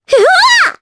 Requina-Vox_Damage_jp_03.wav